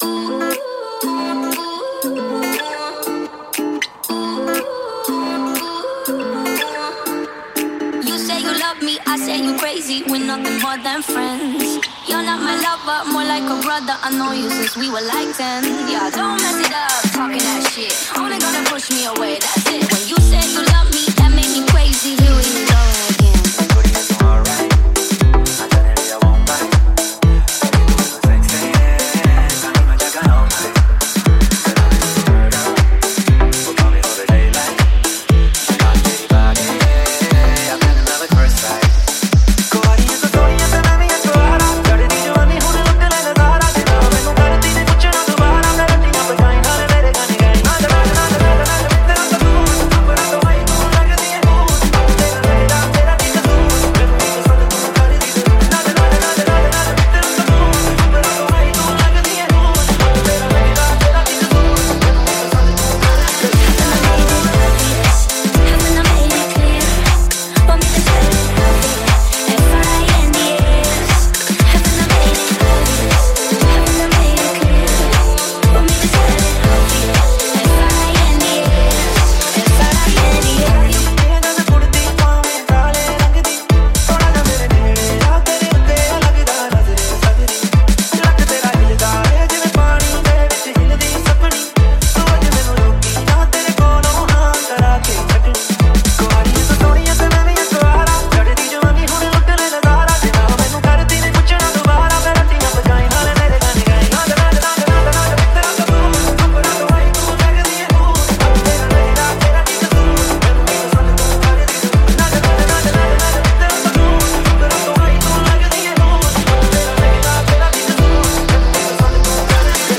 Category : Bollywood DJ Remix Songs